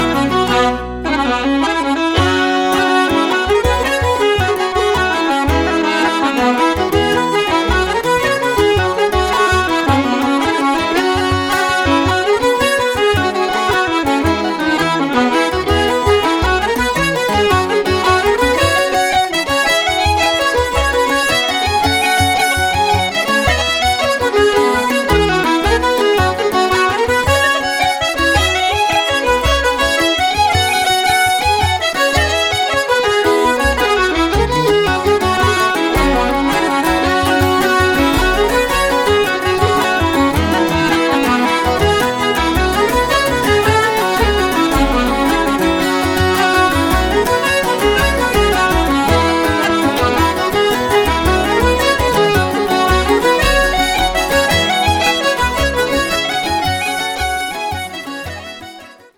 Backing Vocals
Bodhrán
Piano
the album is a collection of 13 eclectic songs and tunes.